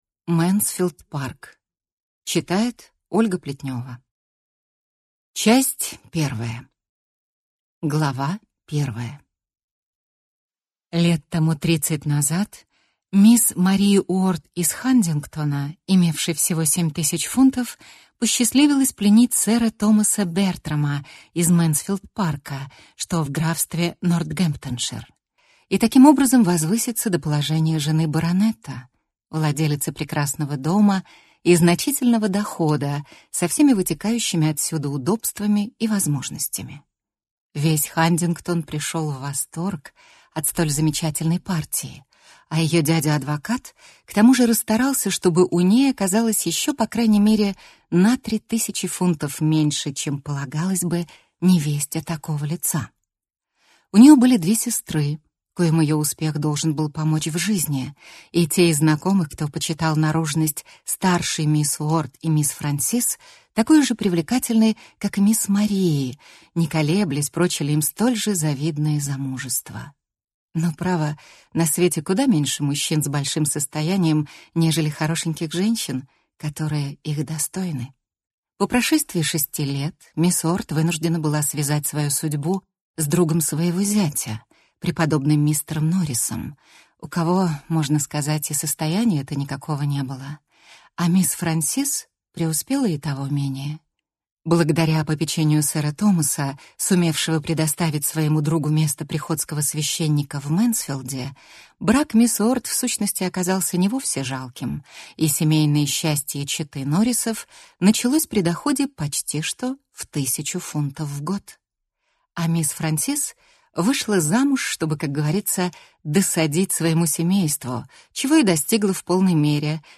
Аудиокнига Мэнсфилд-парк | Библиотека аудиокниг